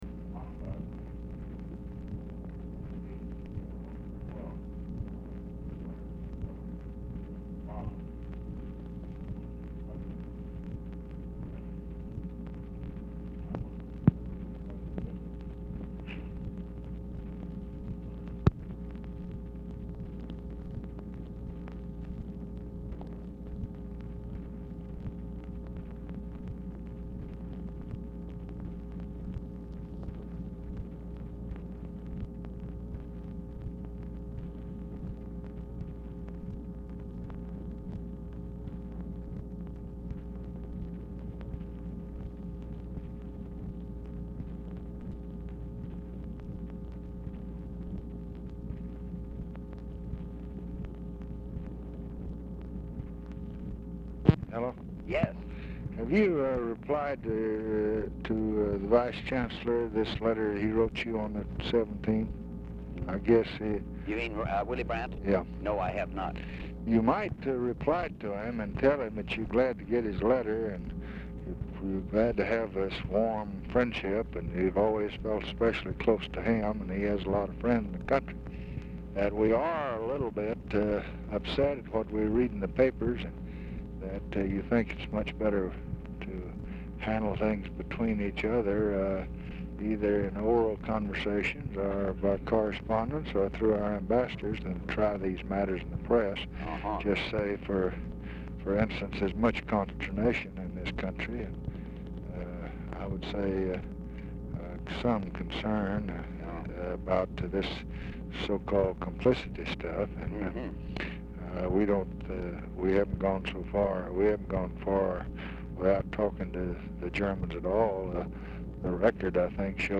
OFFICE CONVERSATION PRECEDES CALL; HHH ON HOLD 0:52;
Format Dictation belt
Specific Item Type Telephone conversation